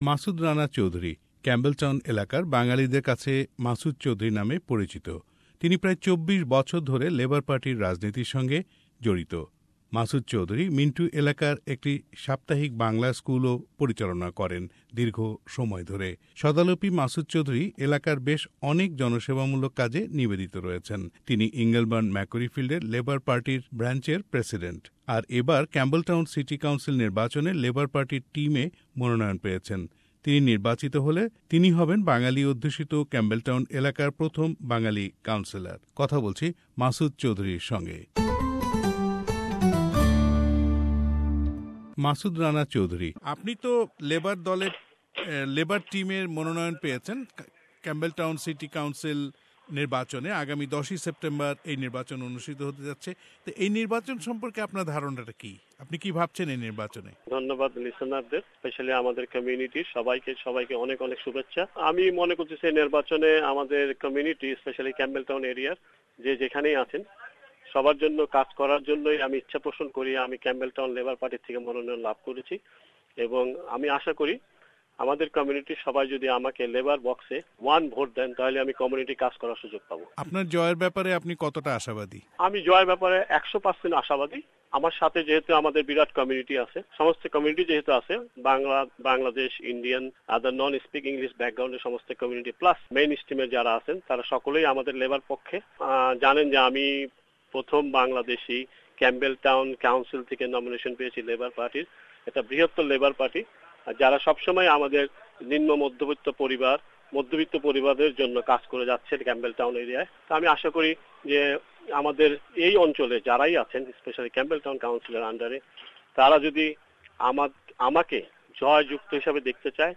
Campbelltown City Council Election : Interview